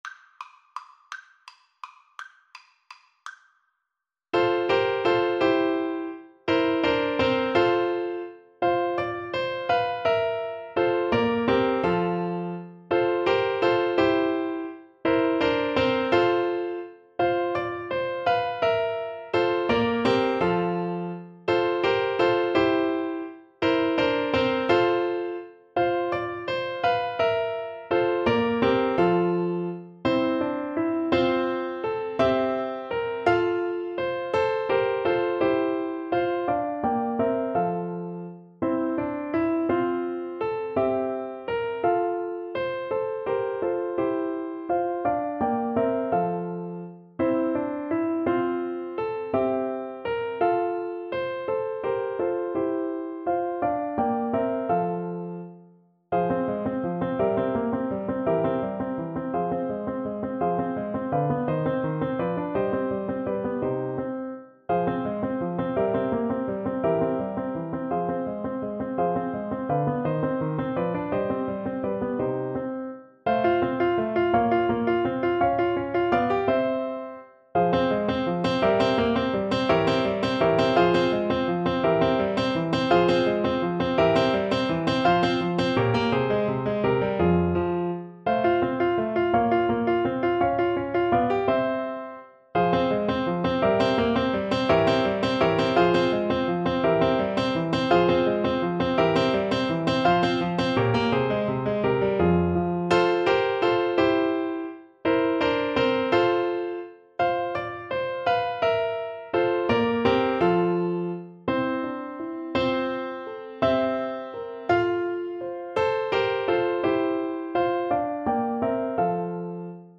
Lustig (Happy) .=56
3/8 (View more 3/8 Music)
Classical (View more Classical French Horn Music)